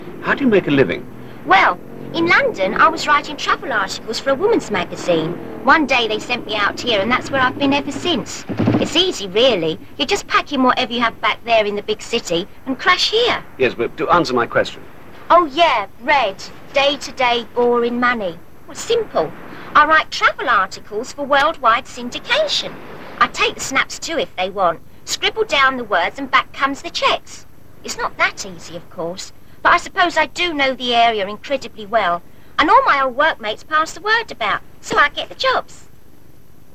On all the CDs, Wendy's pronunciation is clear and studied, and she provides enough inflection to avoid sounding mechanical. It is quite possible her careful tone of speech was intentional, to avoid confusing the listener between her narrative comments and the lines of her character Shirley Brahms.